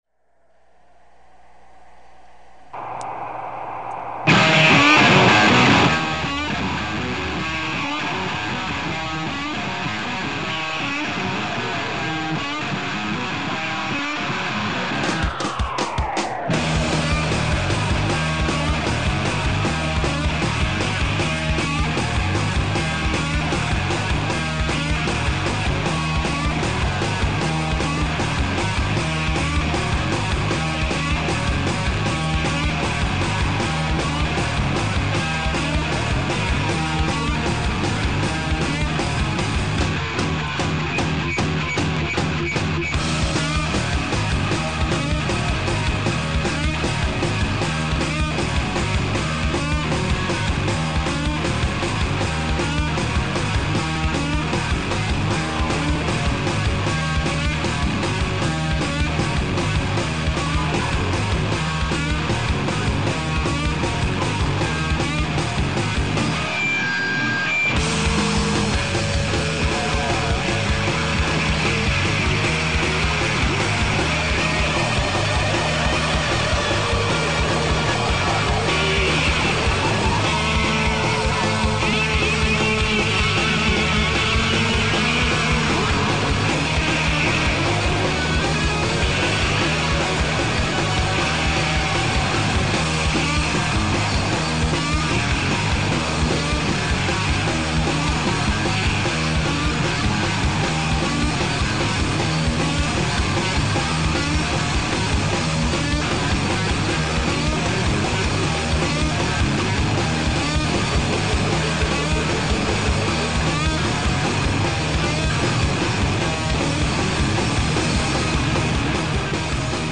Iragandako zuzenekoak ekarriko ditugu gogora. Batetik Rufus Wainwright pop barrokoaren errege bitxiak Donostian emandakoa. Bestetik, Wilco seikotearen rock askea (Ze geixa biopa!).
Muted abadiñoar taldearen bisita. Metal melodikoz eta programazio elektronikoz zipriztindutako “Sena” argitaratu berri dute Baga Biga disko etxean eta gurean izan dira, jator.